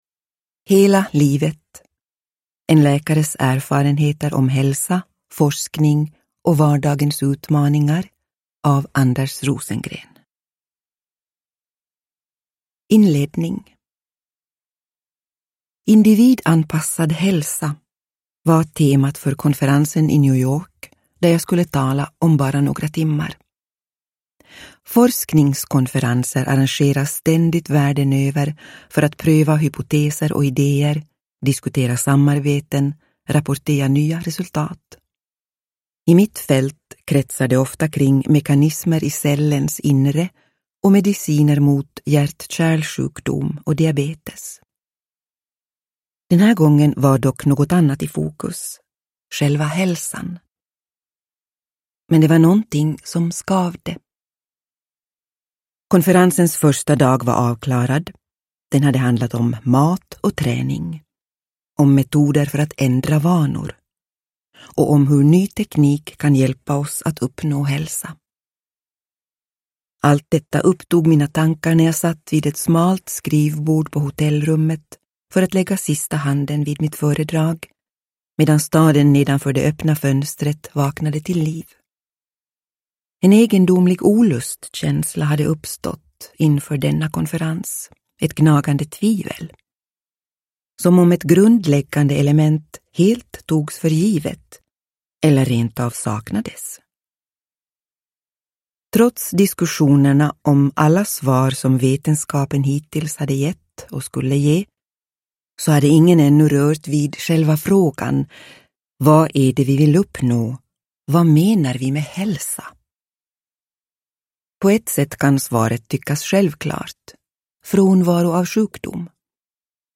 Hela livet : en läkares erfarenheter om hälsa, forskning och vardagens utmaningar – Ljudbok – Laddas ner
Uppläsare: Stina Ekblad